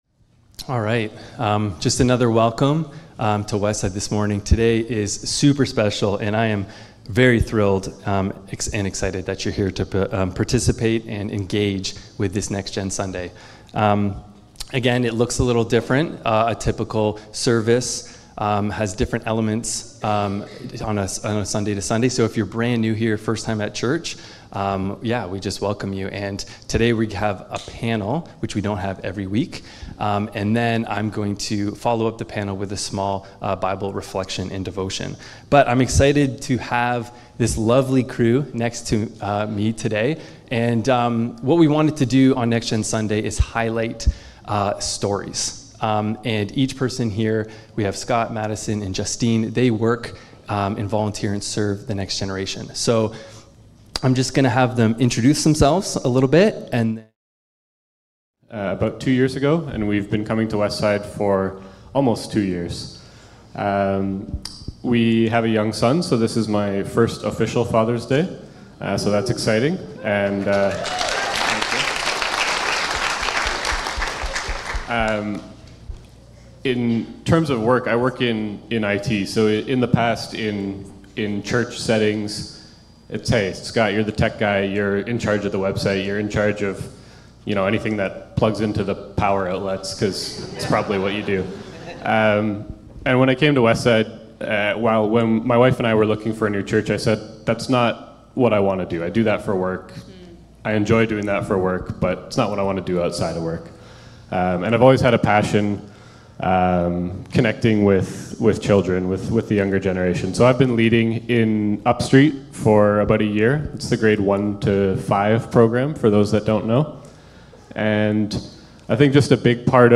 This Sunday we are excited to have a service geared toward the next generation at Westside. Our youth will be leading us in music & worship, hosting on stage, helping with greeting, tech, and our clothing drive. We will also hear some stories from volunteers who serve with our kids, youth, and young adults ministries.